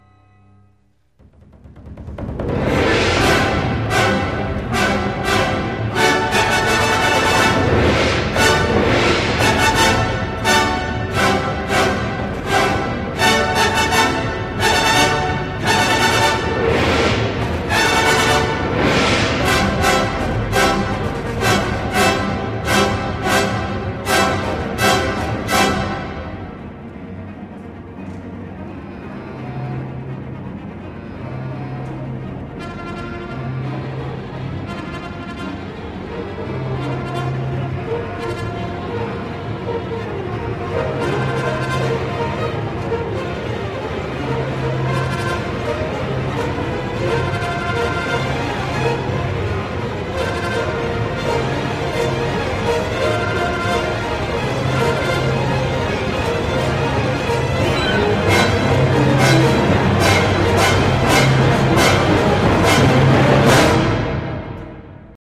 Quarter note = 168